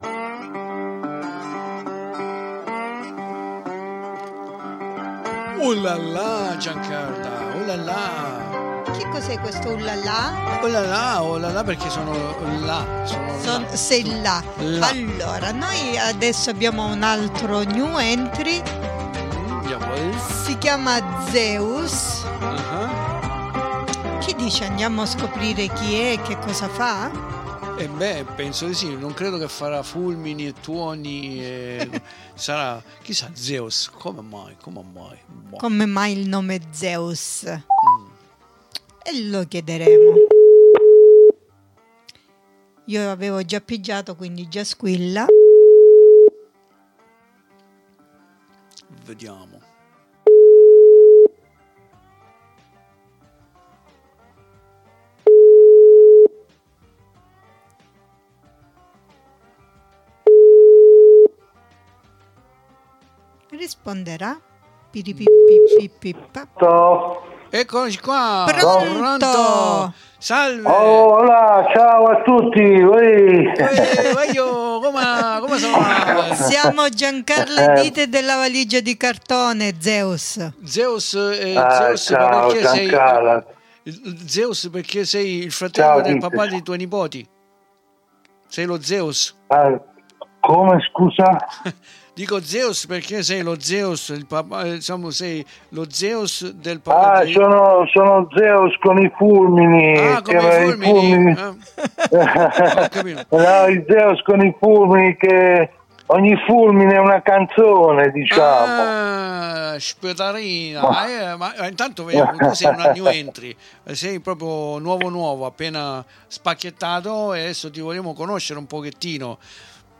CI RACCONTA E SI RACCONTA CON ENFASI.